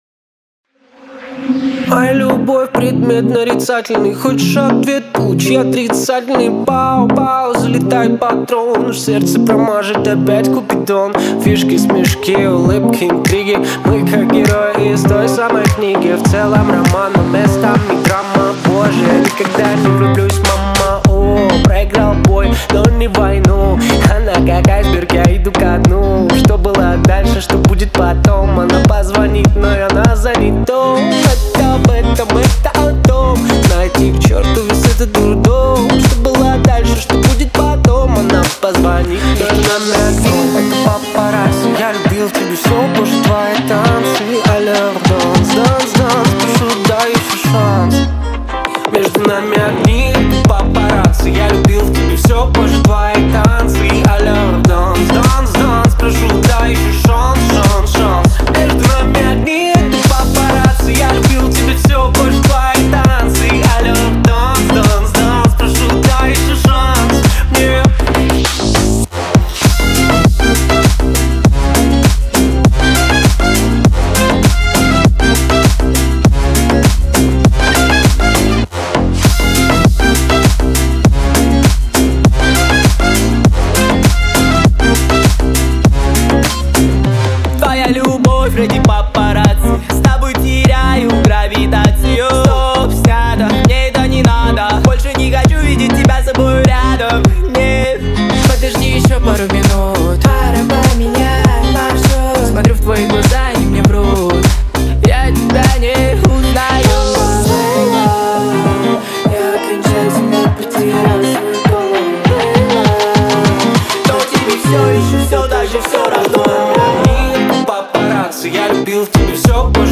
Новинки русской музыки